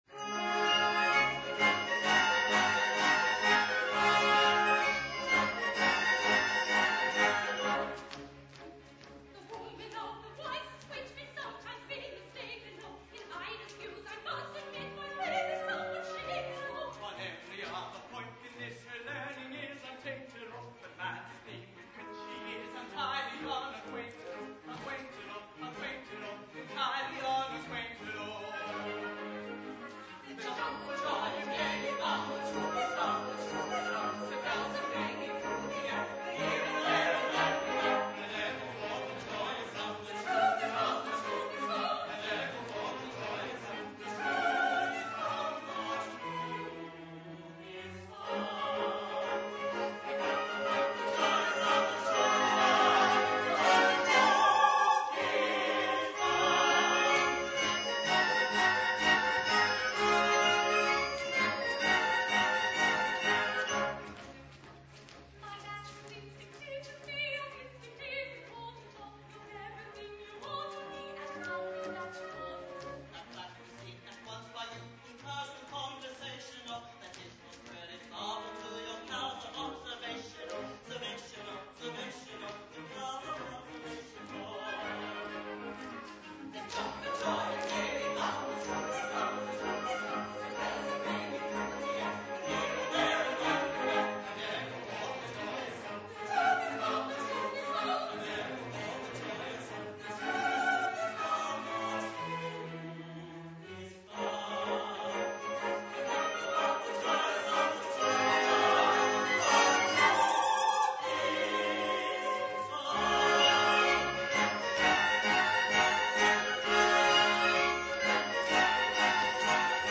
Jeeves Audio Services is pleased to be associated with the Gilbert and Sullivan Society of Victoria, making live recordings of the society's productions.